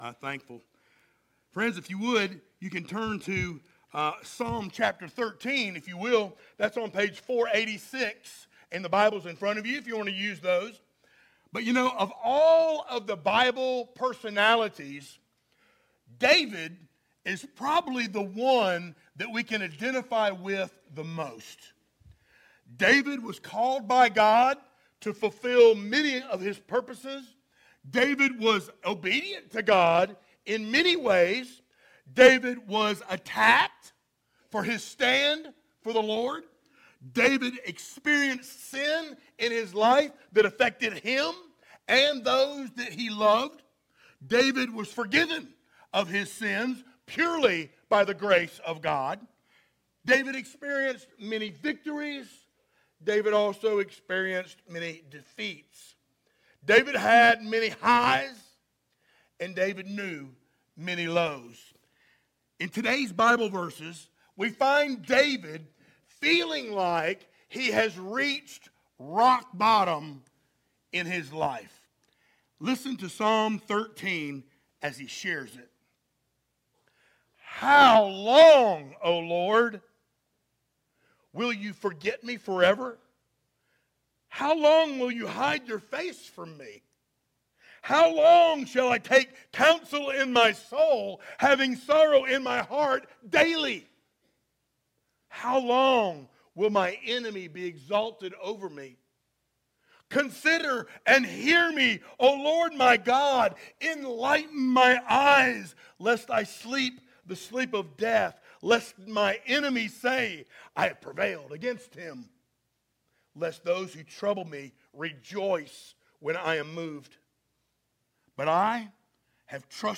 sermons Passage: Psalm 13:1-6 Service Type: Sunday Morning Download Files Notes « Grasping God’s Great Love God’s Way Works!